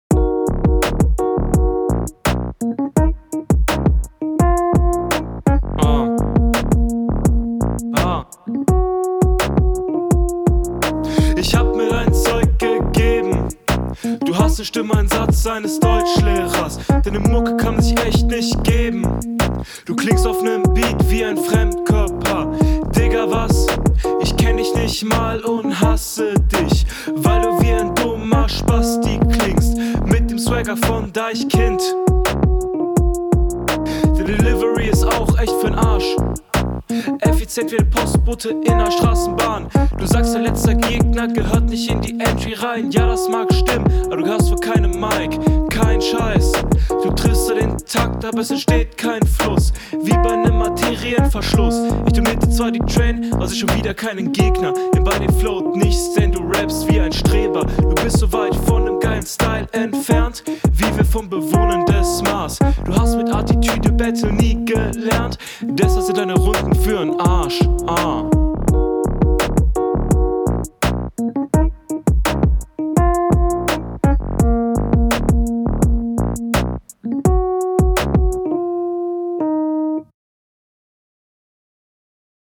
Cooler Beat.
Soundquali wie von dir gewohnt auf sehr hohem Niveau, was Mixing angeht.